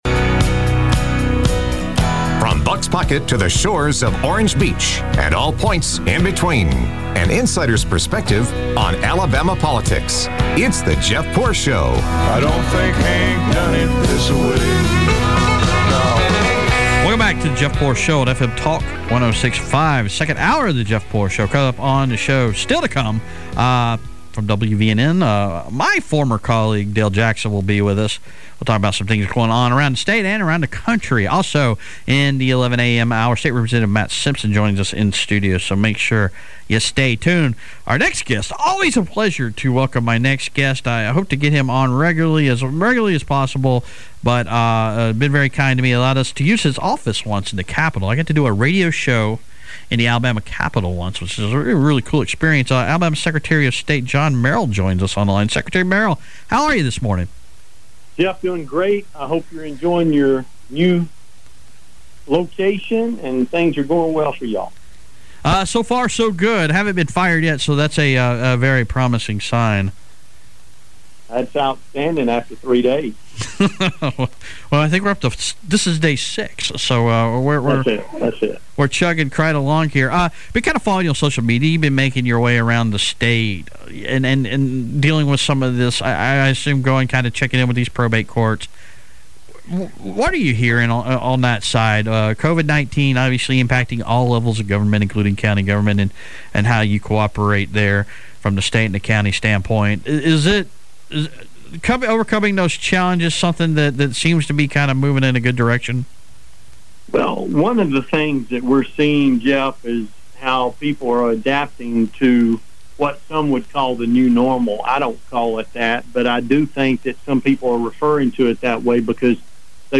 interviews Alabama Secretary of State John Merrill